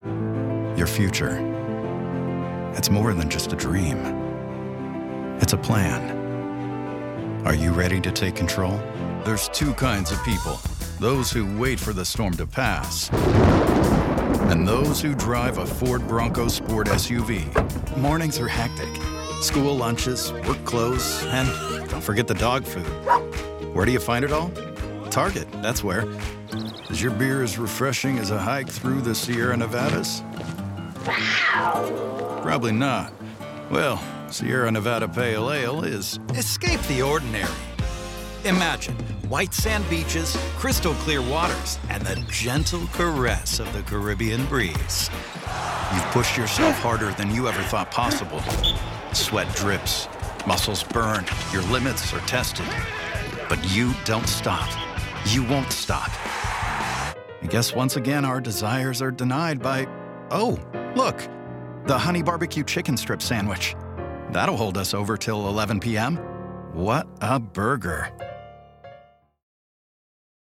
Professional Studio, High Quality Recording with an All American Midwest Charm Male Voice.